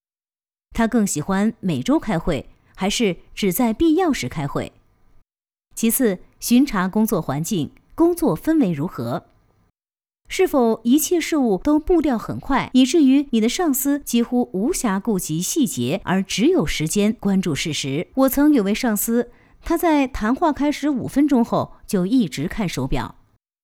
Chinese_Female_048VoiceArtist_2Hours_High_Quality_Voice_Dataset
Text-to-Speech